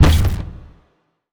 sci-fi_weapon_deep_blaster_shot_01.wav